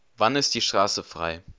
Nexdata/German_Speech_Data_by_Mobile_Phone_Guiding at cce6be88c5f8550eaa474fd1b0d42345edf04dc8